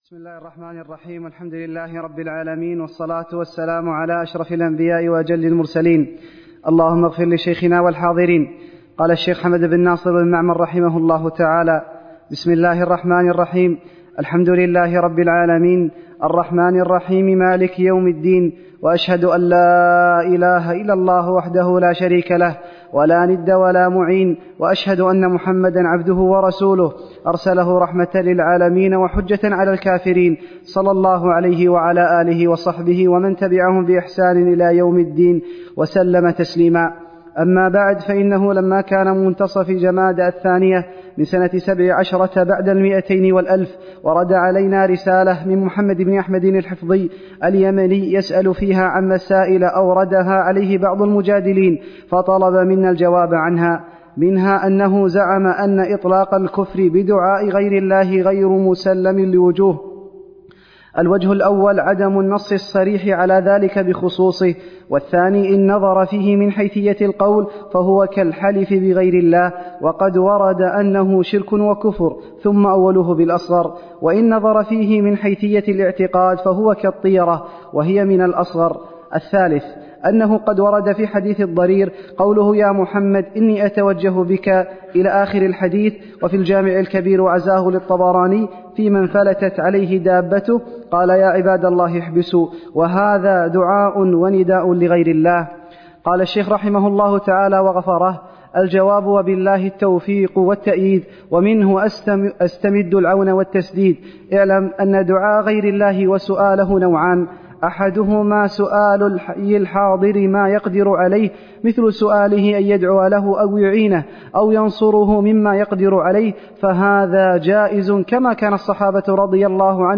عنوان المادة الدرس (1)شرح رسالة الرد على القبوريين لحمد بن معمر تاريخ التحميل السبت 31 ديسمبر 2022 مـ حجم المادة 48.29 ميجا بايت عدد الزيارات 225 زيارة عدد مرات الحفظ 110 مرة إستماع المادة حفظ المادة اضف تعليقك أرسل لصديق